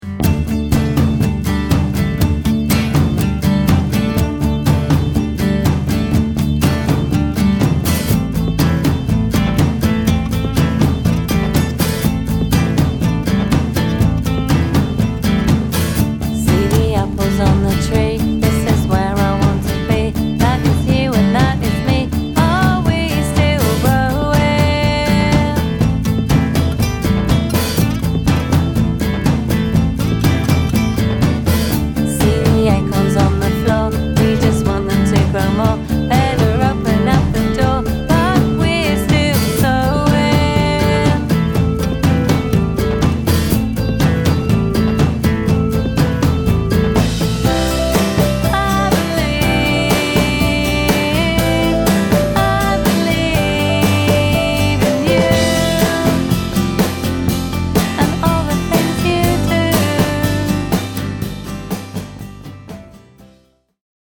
Rough demo tracks